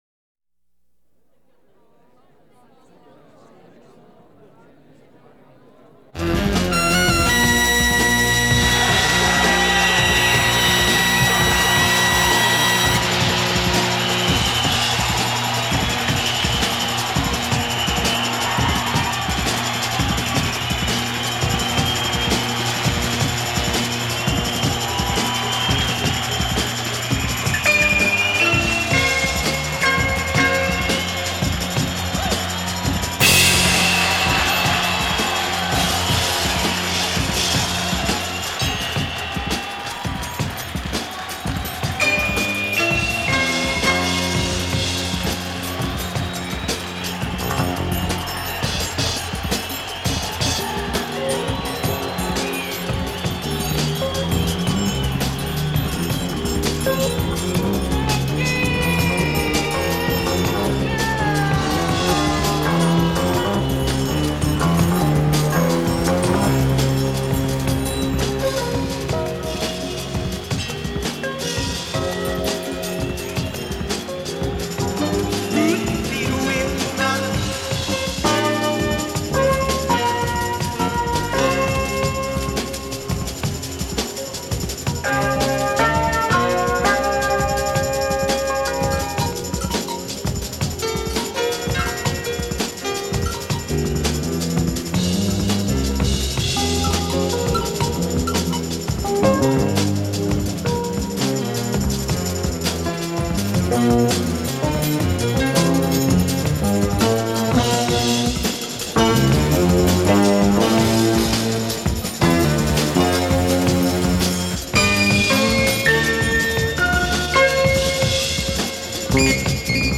Fusion